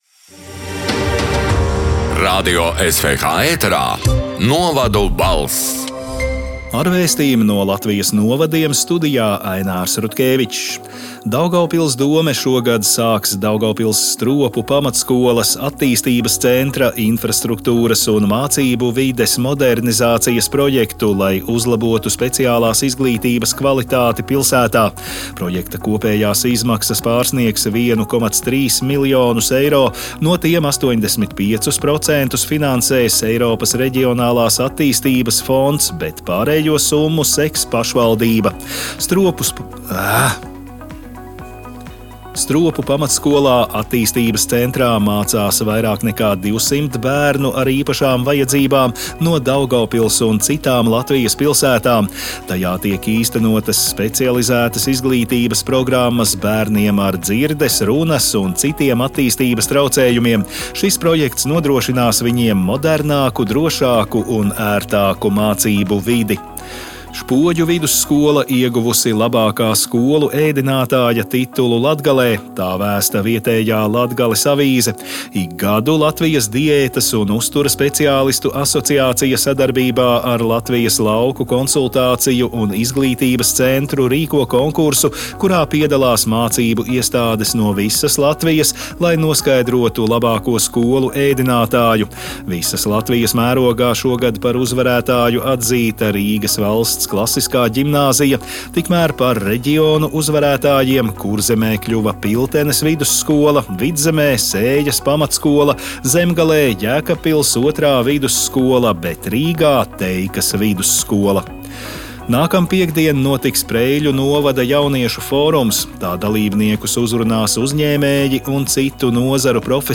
“Novadu balss” 28. marta ziņu raidījuma ieraksts: